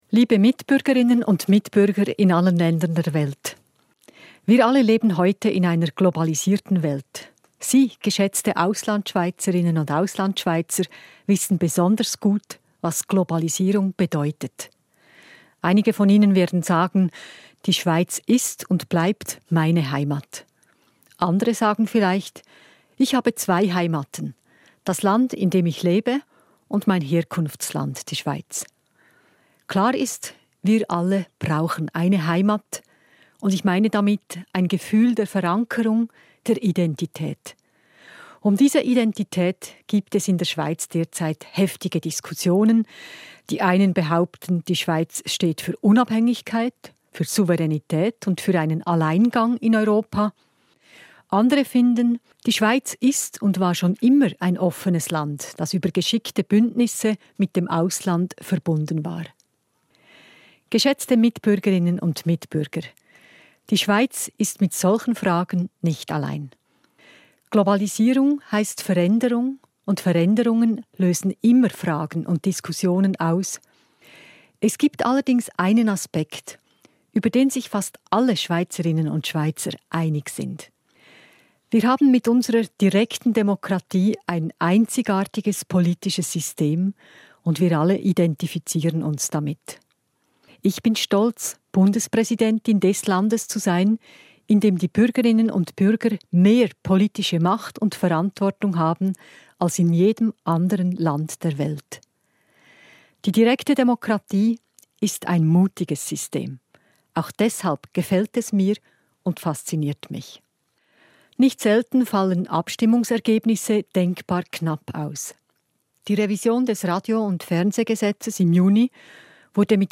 1.-August-Rede der Bundespräsidentin